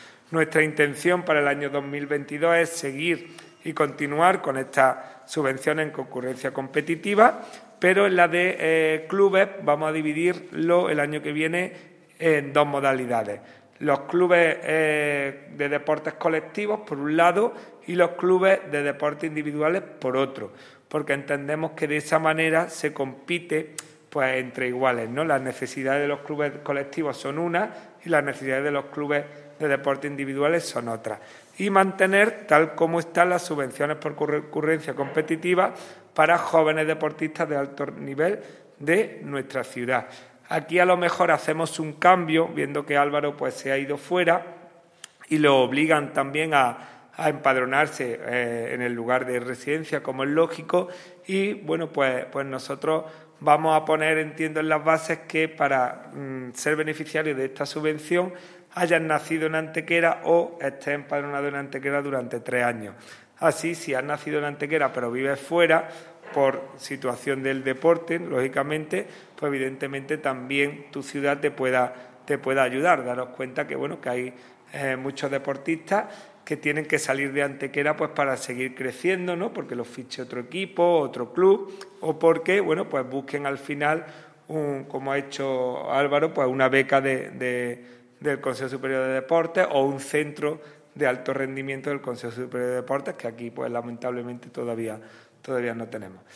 El teniente de alcalde delegado de Deportes, Juan Rosas, ha informado en rueda de prensa sobre las subvenciones municipales por concurrencia competitiva que ha otorgado el Ayuntamiento de Antequera en el año 2021 tanto a clubes deportivos como a deportistas jóvenes de alto nivel. 60.000 euros ha sido el total de las ayudas ya resueltas –el 80 por ciento de ellas ya están incluso ingresadas– que se han aportado desde el Consistorio para ayudar a competir tanto a 27 clubes (3 más que en 2020) y 8 deportistas individuales.
Cortes de voz